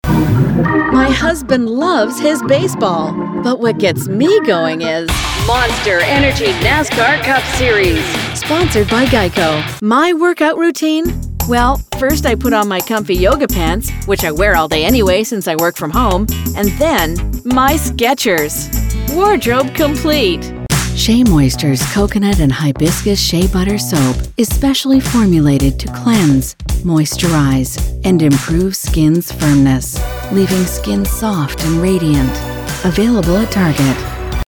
Inglês (canadense)
Sou um dublador profissional não sindicalizado (neutro norte-americano {canadense ou americano}) com um estúdio totalmente equipado...
Noivando
Corporativo
Suave